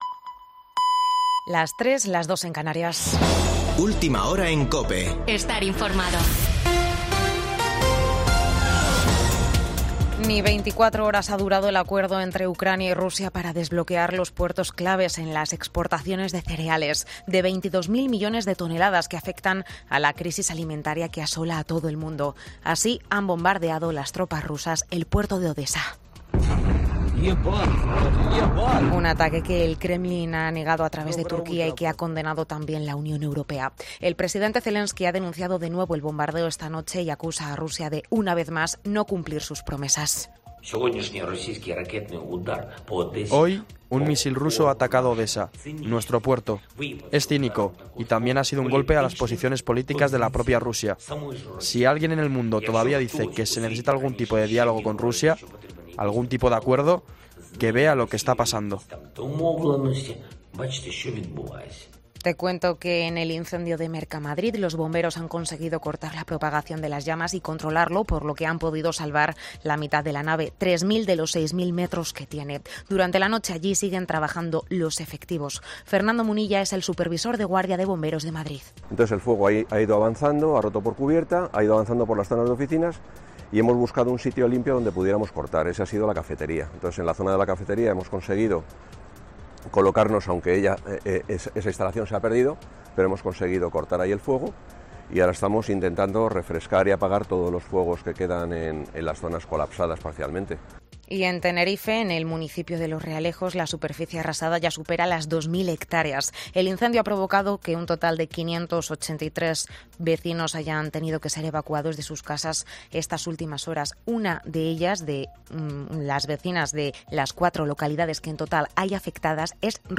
Boletín de noticias de COPE del 24 de julio de 2022 a las 03:00 horas